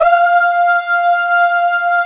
Choir(4)_F5_22k.wav